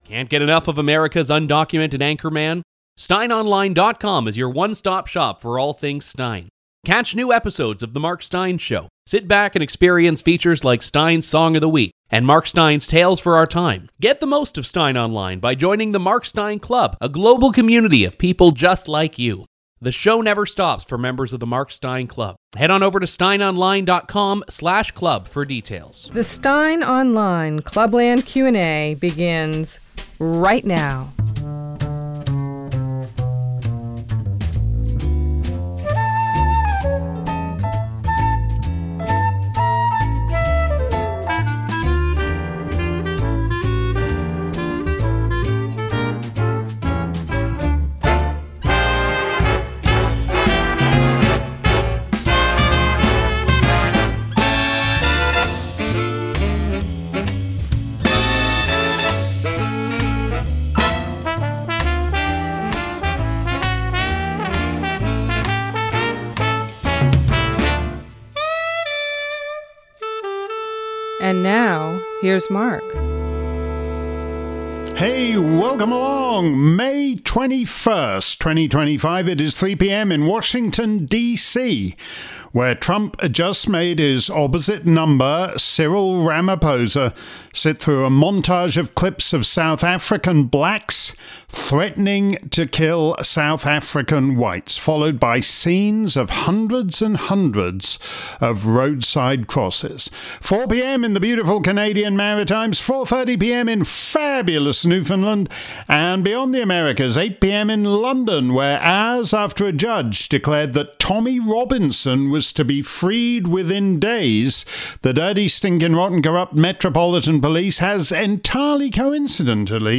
If you missed today's edition of Steyn's Clubland Q&A live around the planet, here's the action replay. This week's show covered a range of topics from Trump and Ramaphosa to the Remigration Summit in Italy.